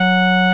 mariopaint_car.wav